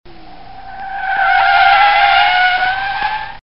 Звуки тормоза автомобиля
На этой странице собрана коллекция звуков тормозов автомобилей: от резкого визга шин до плавного скрежета.
Звук резкого торможения с визгом и сползанием шин по асфальту